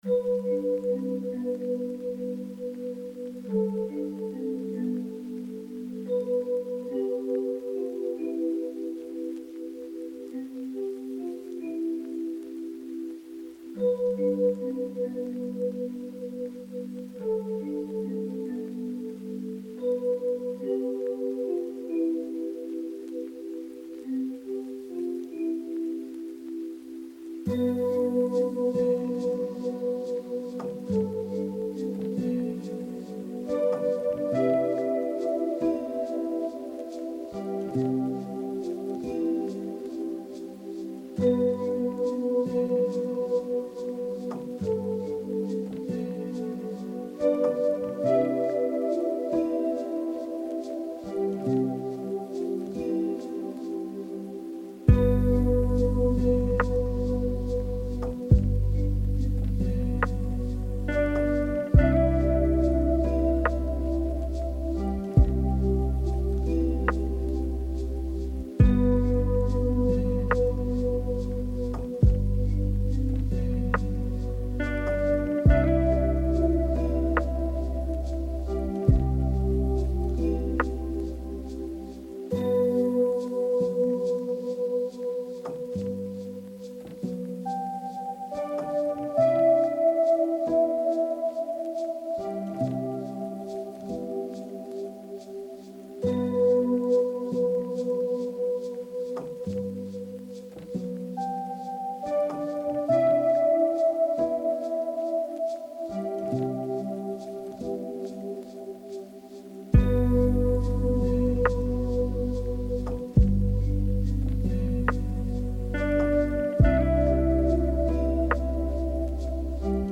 آرامش بخش